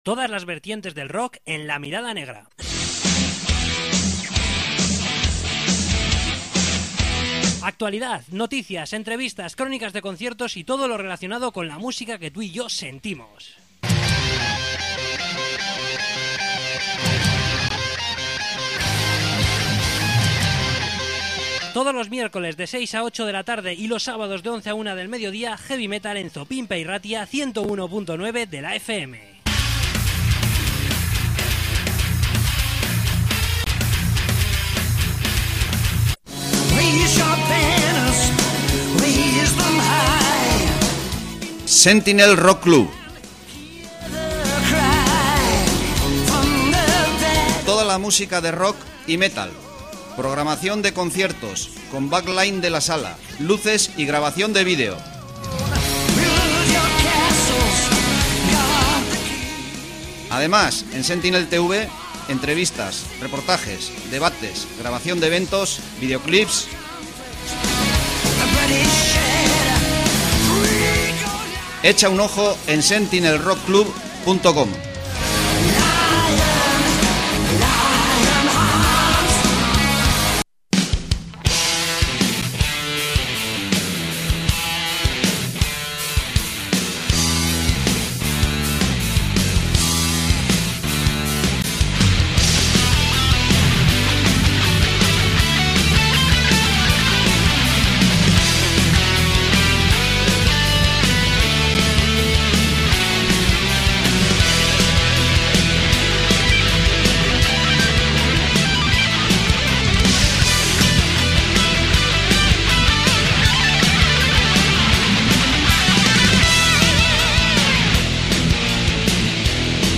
Entrevista con Crownless